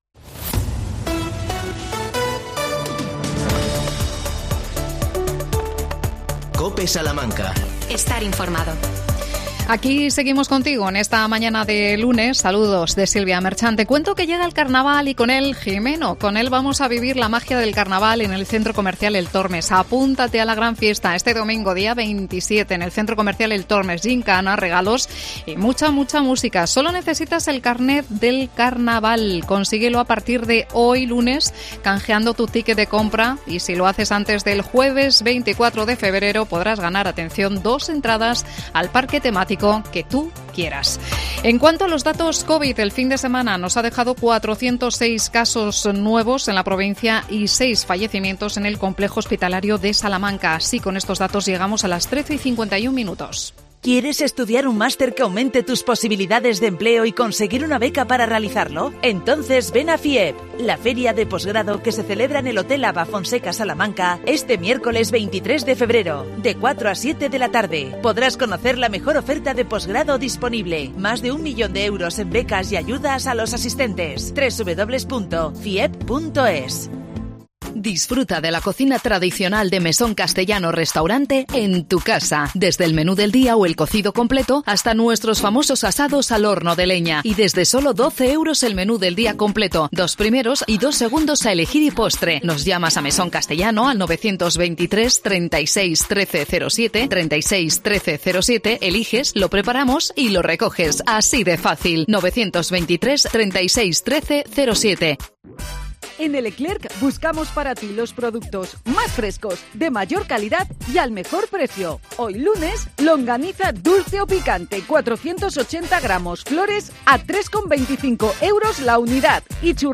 Entrevistamos a su alcalde Guillermo Rivas.